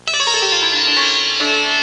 Sitar Sound Effect
Download a high-quality sitar sound effect.
sitar.mp3